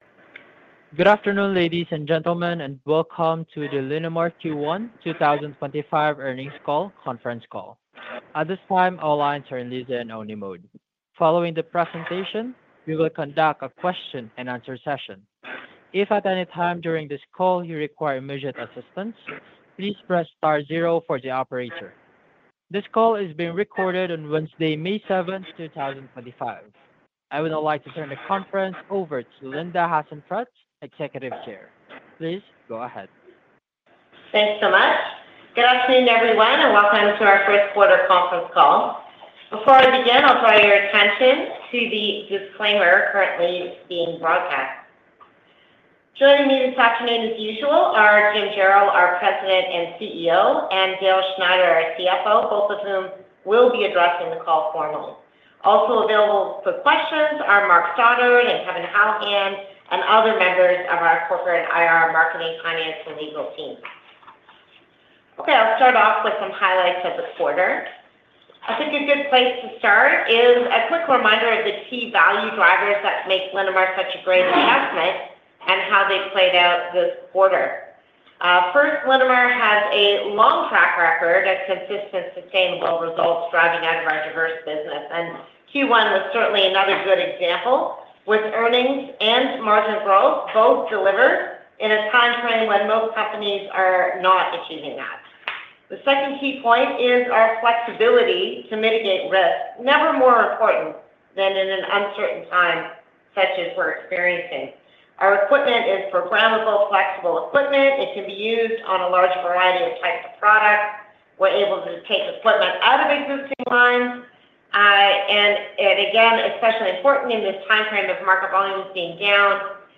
Linamar-Q1-2025-Earnings-Call.mp3